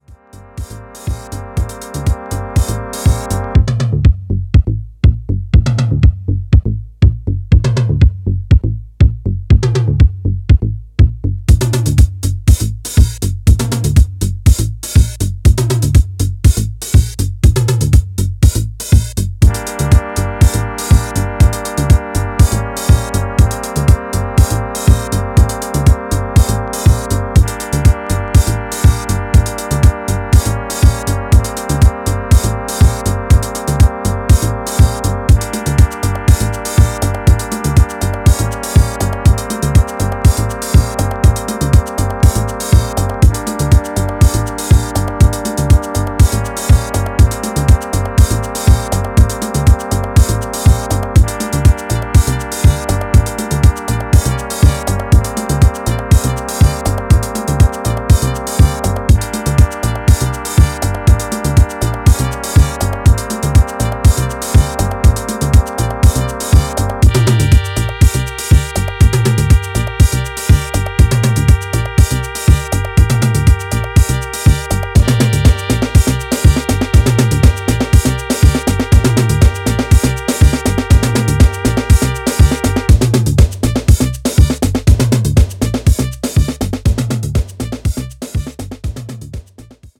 House Techno Acid